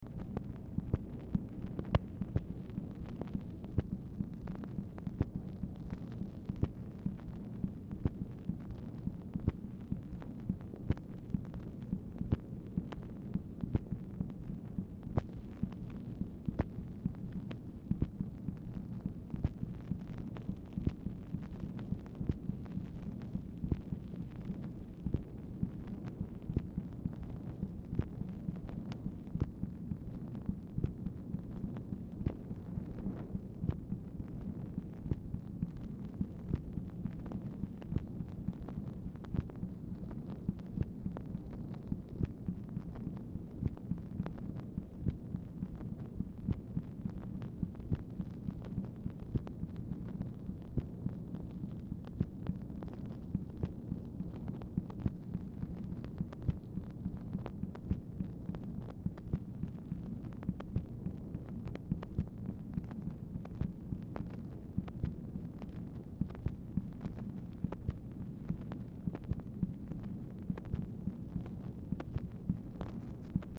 Telephone conversation # 8644, sound recording, MACHINE NOISE, 8/26/1965, time unknown | Discover LBJ
Format Dictation belt